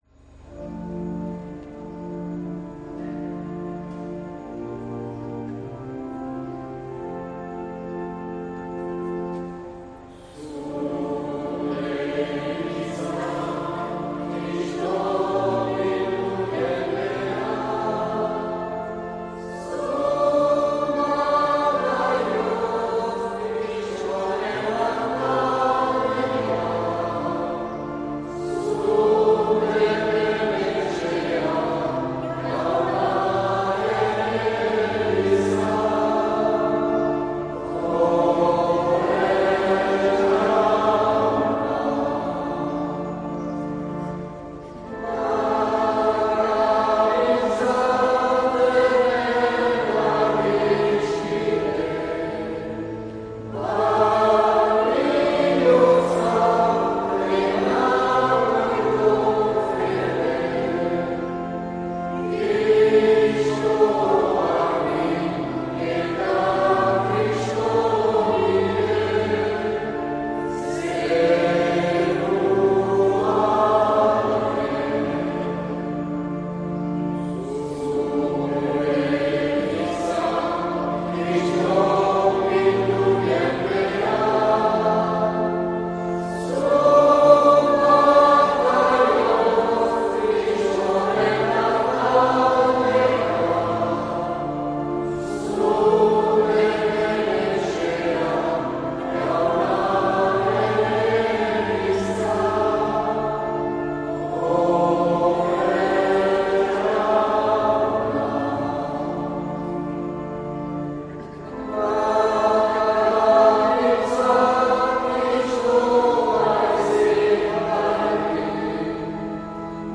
Accueil \ Emissions \ Vie de l’Eglise \ Célébrer \ Igandetako Mezak Euskal irratietan \ 2023-07-09 Urteko 14.